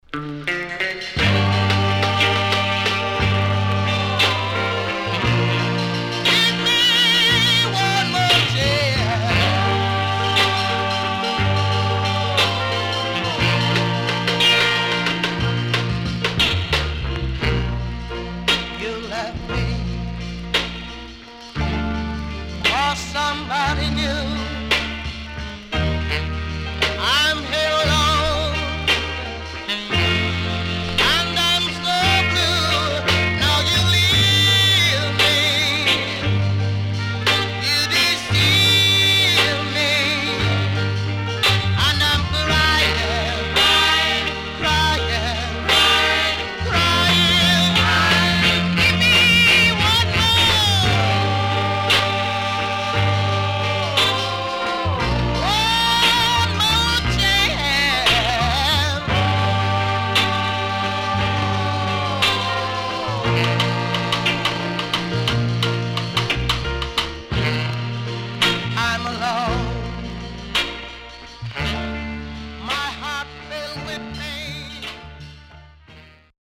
SKA
INST 60's
SIDE A:うすいこまかい傷ありますがノイズ目立ちません。